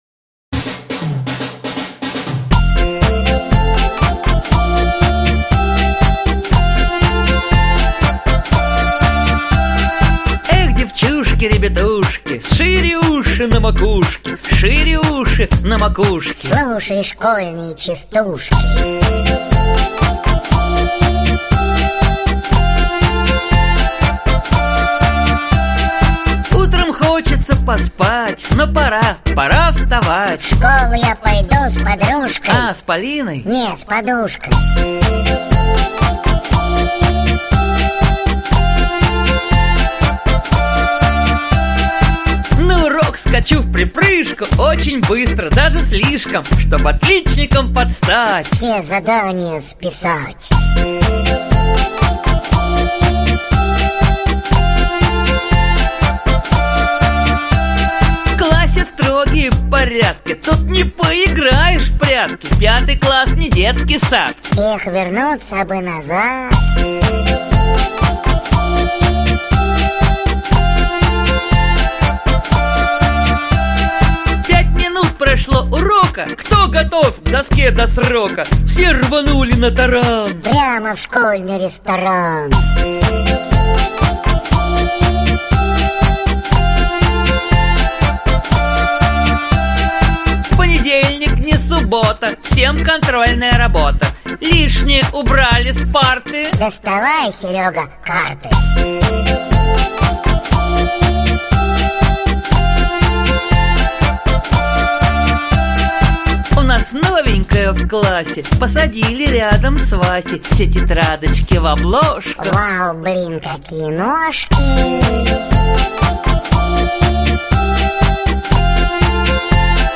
Аудиокнига Детские частушки и потешки | Библиотека аудиокниг
Aудиокнига Детские частушки и потешки Автор Юрий Кудинов Читает аудиокнигу Актерский коллектив.